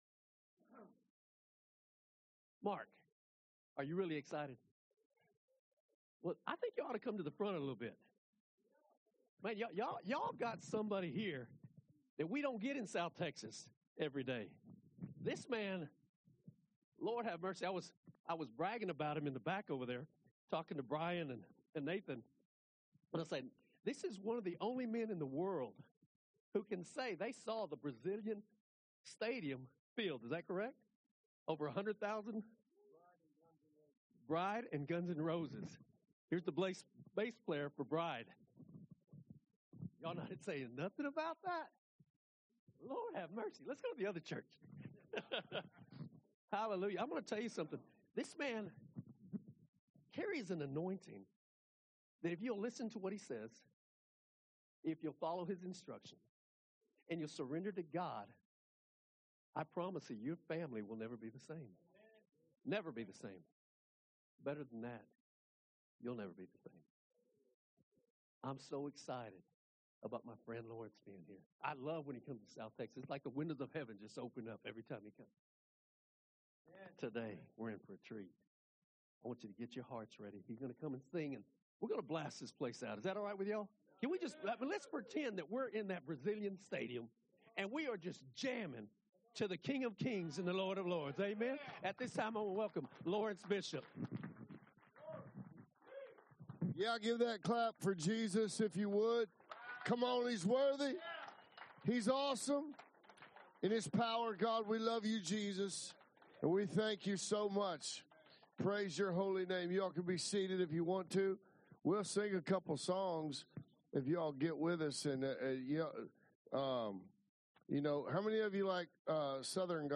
Audio of Past Bible Studies Enjoy some encouraging words from Men of Prayer!